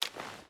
Footsteps / Water
Water Run 2.wav